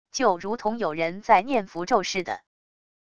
就如同有人在念符咒似的wav音频